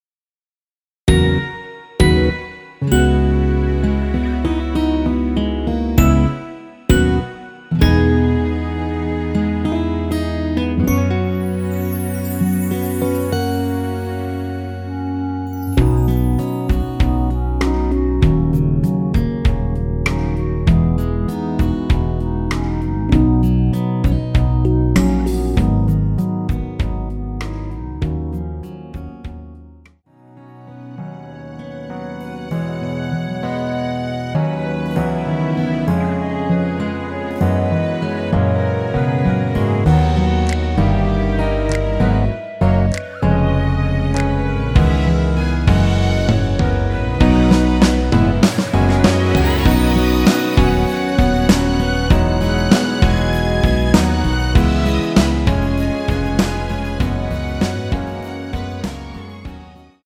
원키에서(-3)내린 멜로디 포함된 MR입니다.
앞부분30초, 뒷부분30초씩 편집해서 올려 드리고 있습니다.
중간에 음이 끈어지고 다시 나오는 이유는
(멜로디 MR)은 가이드 멜로디가 포함된 MR 입니다.